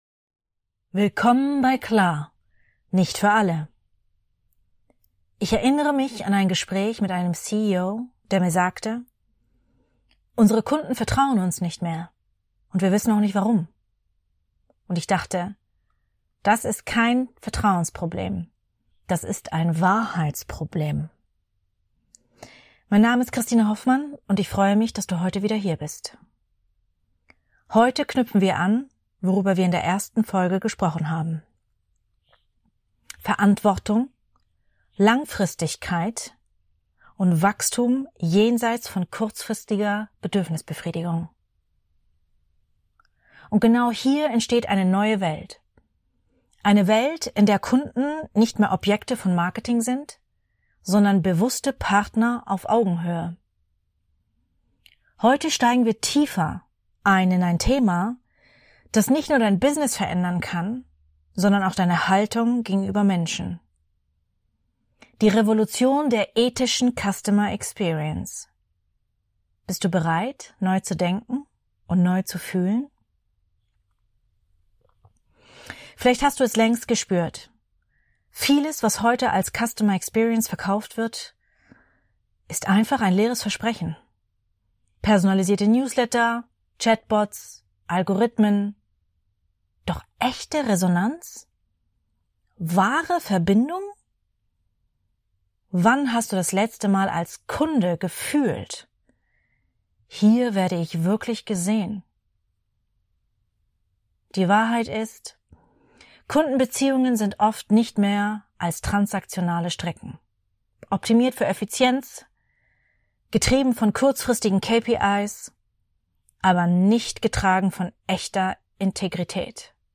Hinweis: Falls du Wassergeplätscher und Pfotenschritte hörst – das ist Chewie, mein vierbeiniger Co-Host.